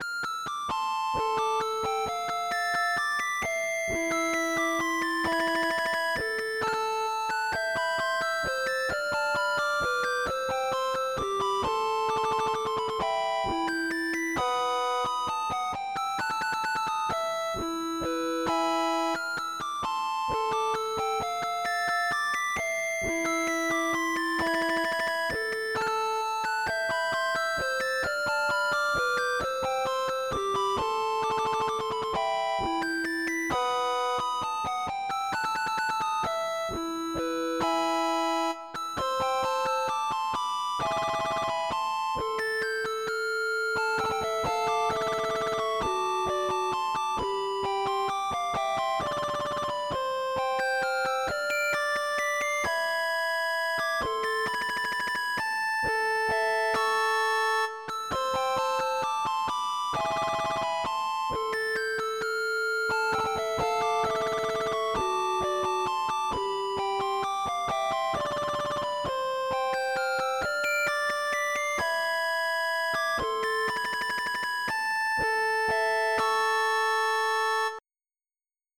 MIDI Music File
courante.mp3